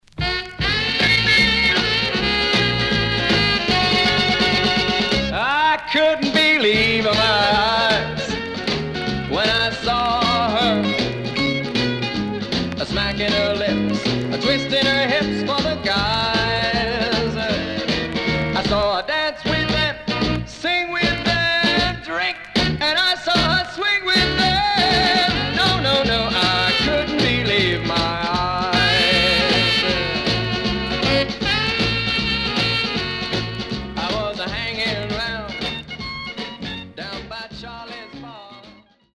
SIDE A:全体的にチリノイズがあり、所々プチノイズ入ります。